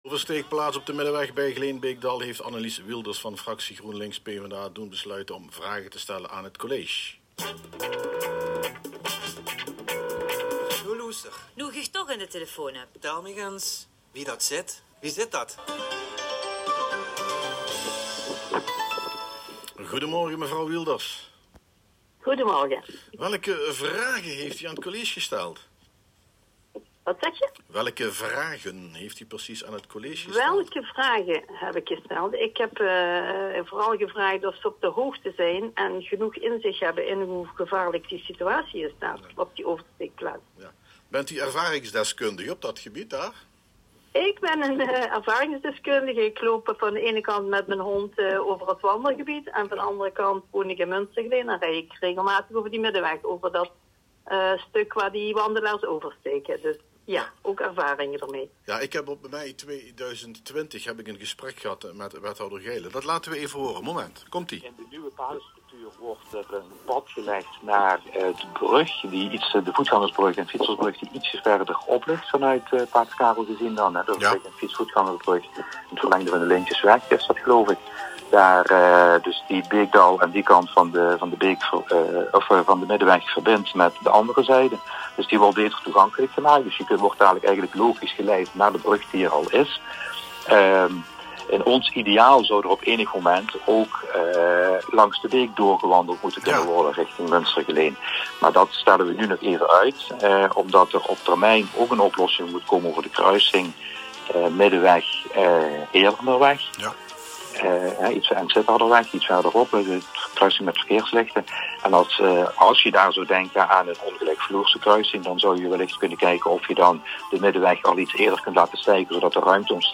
Luister hier het interview terug met ons raadslid Annelies Wielders op Bie Os:
Annelies-Wielders-in-Forum-op-maandag-van-Bie-Os.m4a